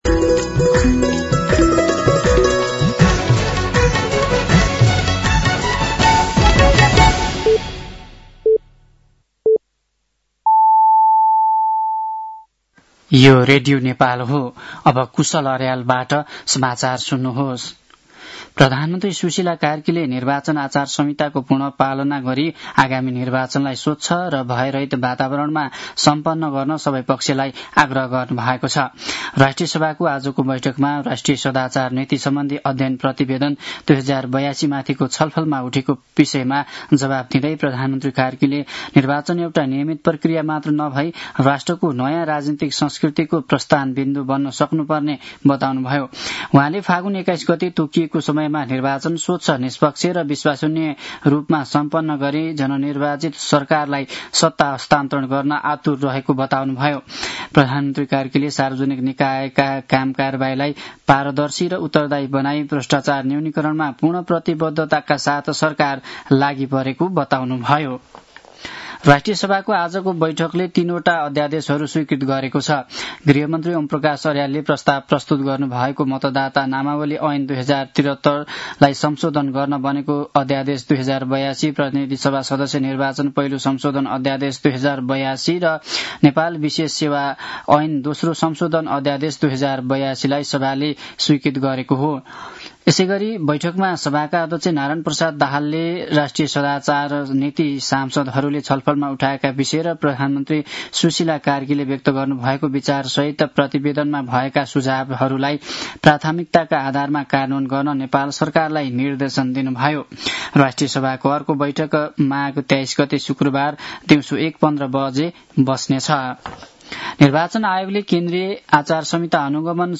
साँझ ५ बजेको नेपाली समाचार : १९ माघ , २०८२
5.-pm-nepali-news-1-1.mp3